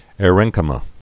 (âr-ĕngkə-mə)